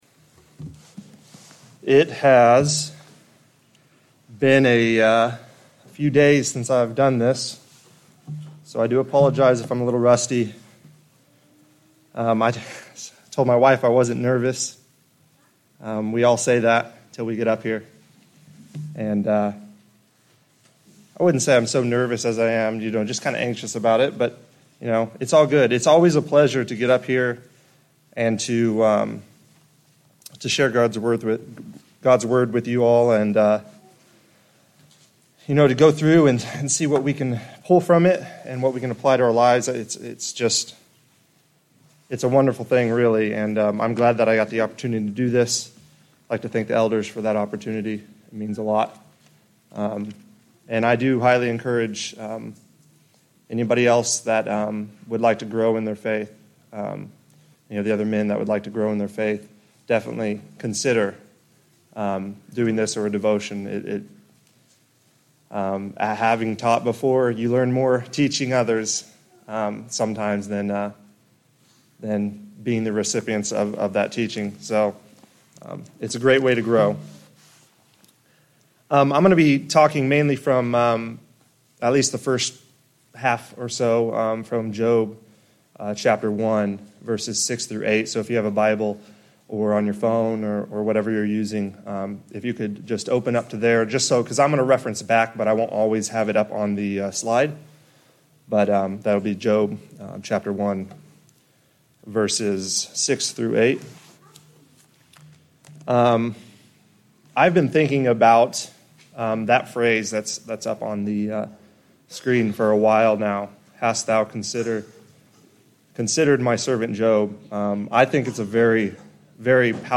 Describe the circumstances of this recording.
Job 1:6-8 Service Type: Sunday Morning Worship I've been thinking about the phrase